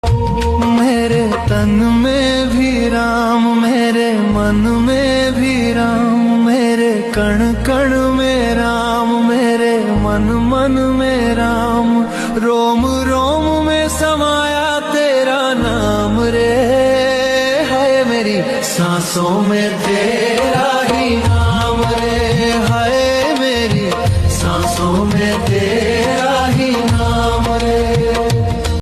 • Quality: High / Clear Audio
• Category: Devotional / Bhajan Ringtone